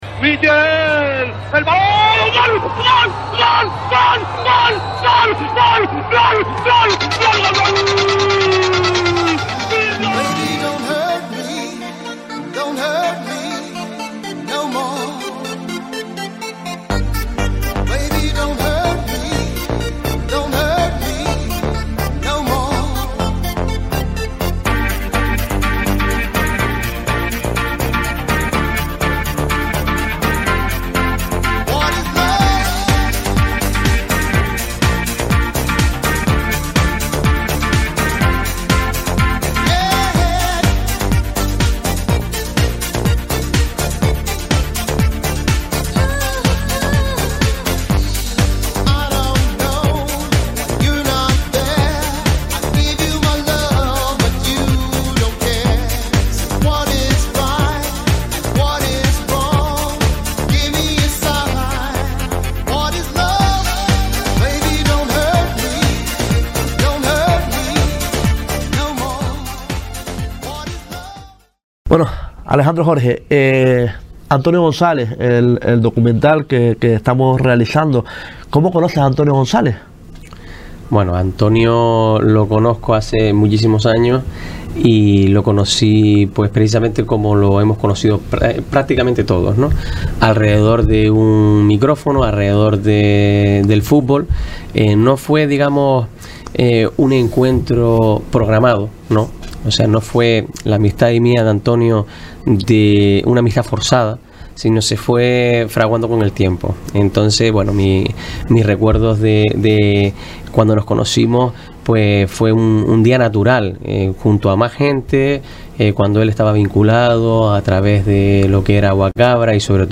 Nos habla Alejandro Jorge, Alcalde de Pájara.